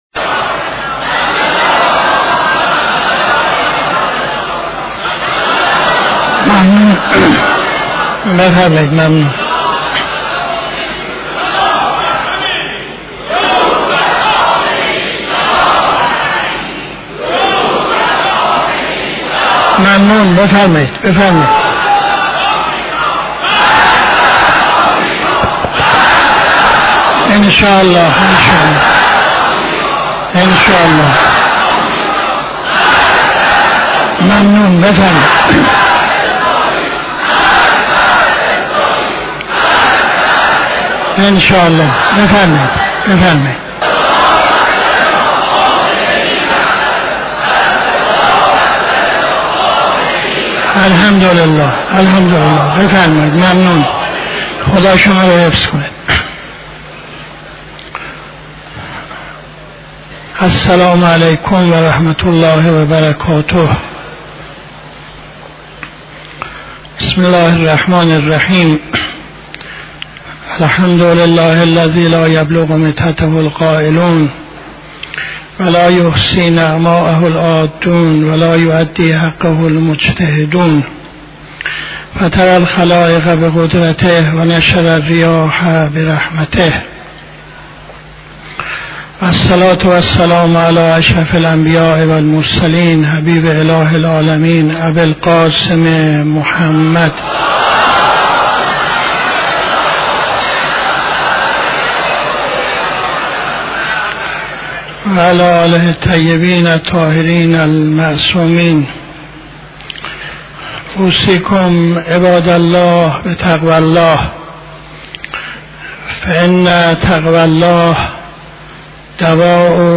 خطبه اول نماز جمعه 07-08-78